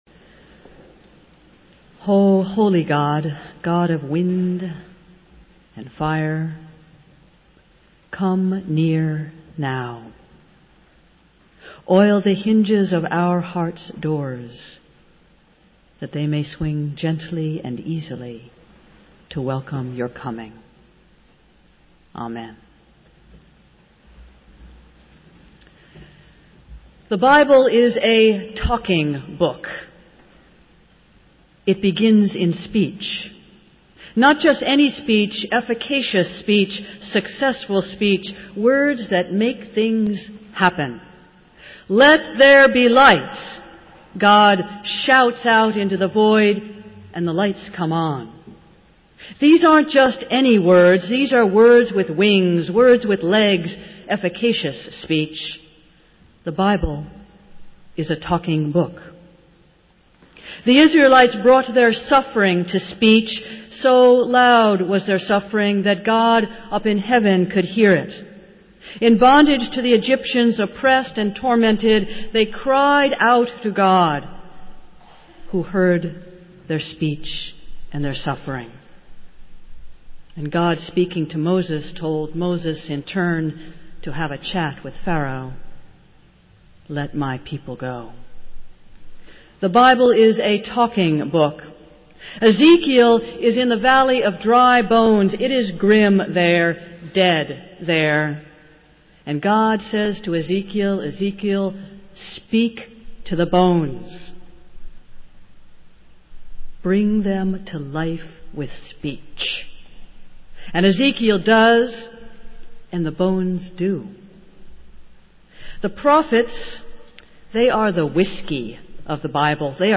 Festival Worship - Pentecost Sunday